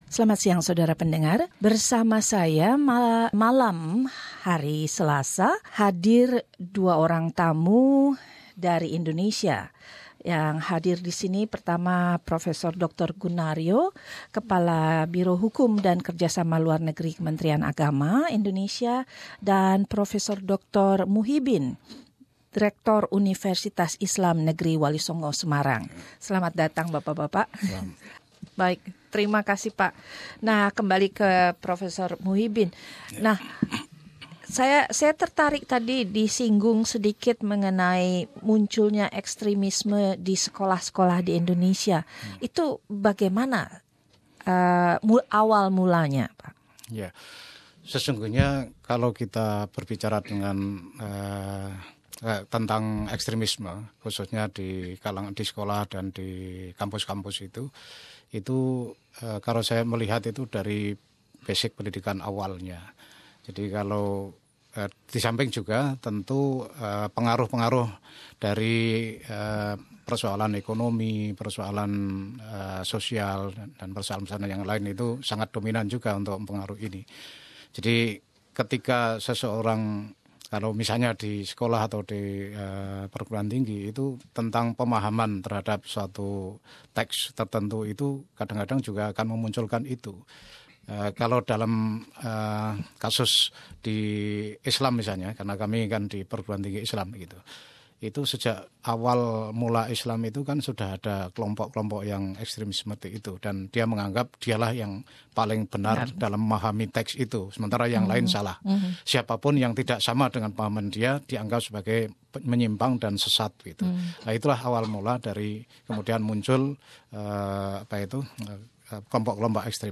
SBS Indonesian berbincang-bincang mengenai ekstremisme agama di Indonesia, dan peran pendidikan dalam menanggal ektremisme atau radikalisme agama.